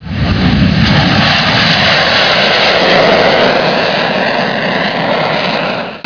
Som do Motor de Um MiG-21Bis
mig21sound.wav